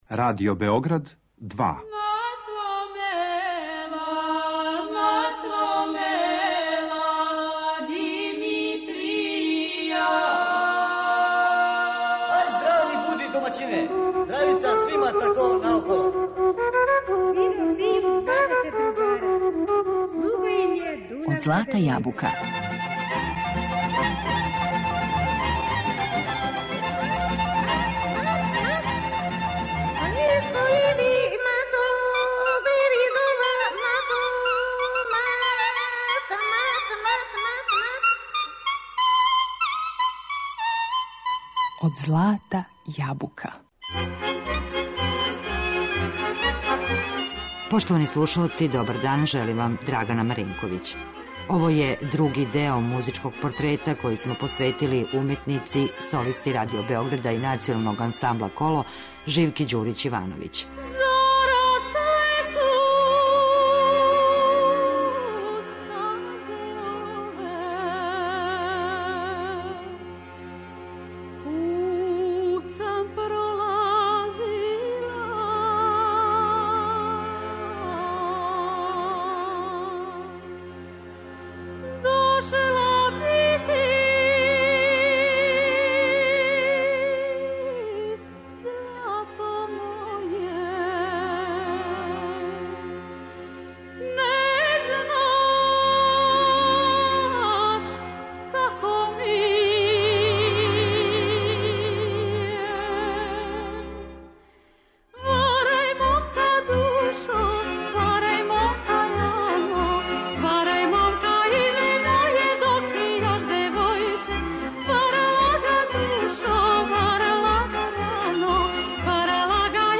Музички портрет